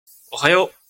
ダウンロード 男性_「おはよう」
挨拶男性